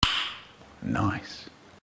spatial sound
click.ogg